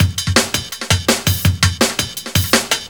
Alzir Break - 4A.wav